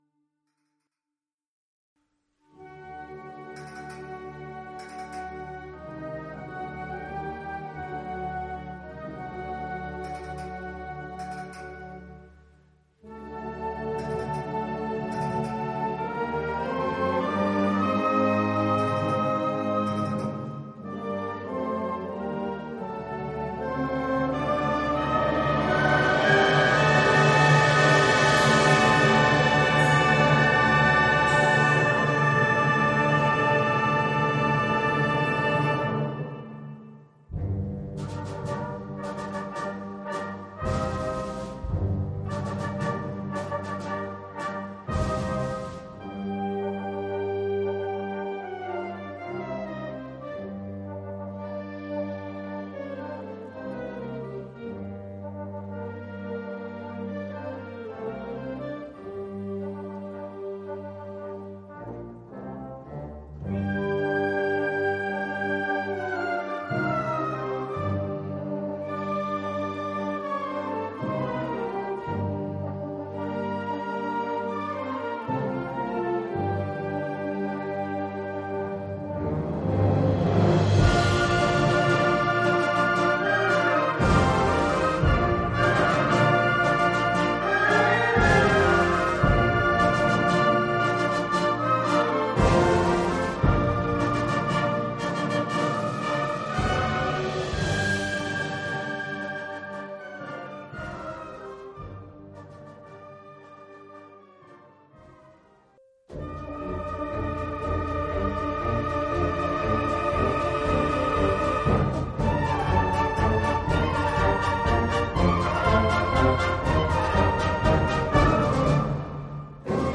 Genre musical : Classique
Collection : Harmonie (Orchestre d'harmonie)
Oeuvre pour orchestre d’harmonie.